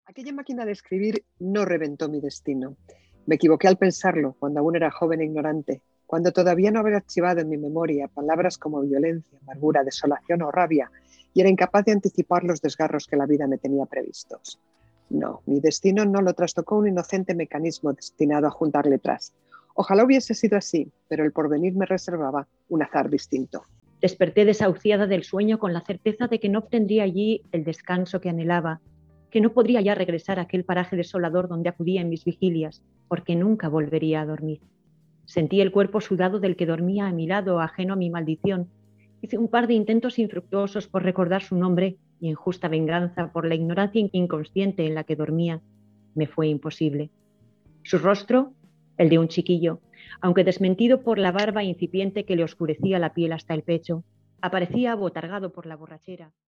Por último, María Dueñas y Dolores Redondo leyeron, en directo, pequeños